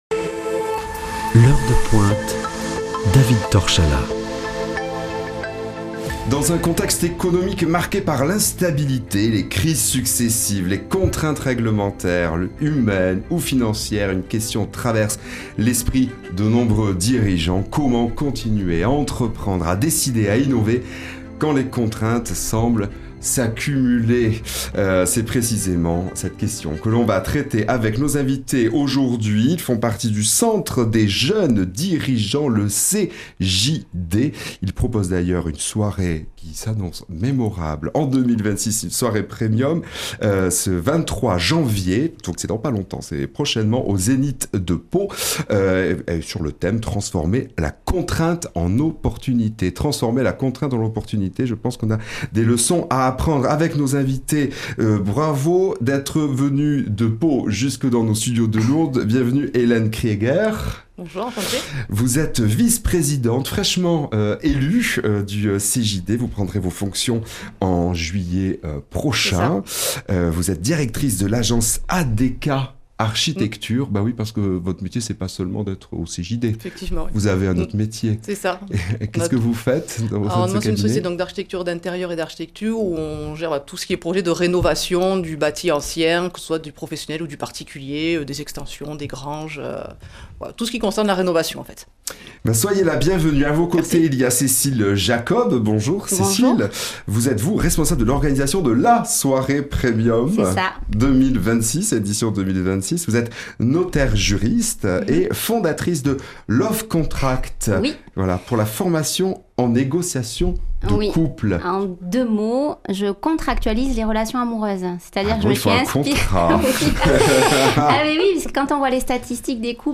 reçoit des membres du Centre des Jeunes Dirigeants (CJD) Béarn pour une émission consacrée aux défis actuels du monde de l’entreprise.